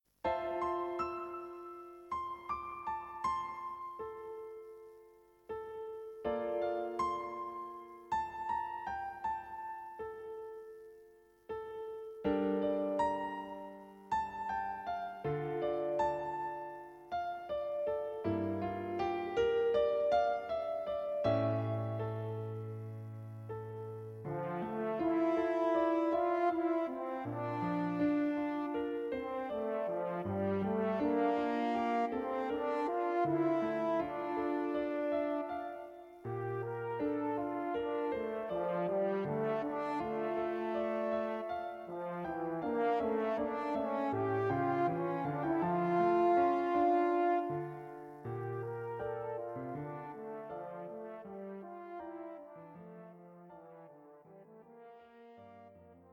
A charming little piece for Horn and Piano.